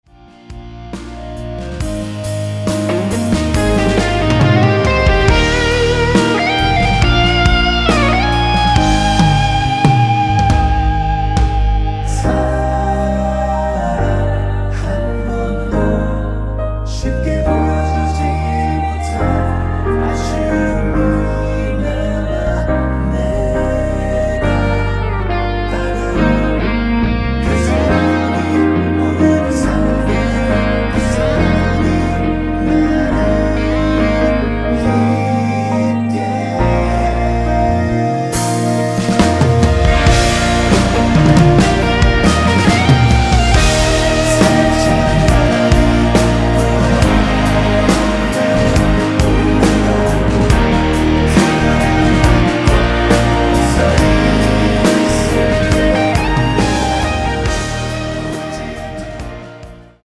원키에서(-1)내린 멜로디와 코러스 포함된 MR입니다.(미리듣기 확인)
Eb
앞부분30초, 뒷부분30초씩 편집해서 올려 드리고 있습니다.
중간에 음이 끈어지고 다시 나오는 이유는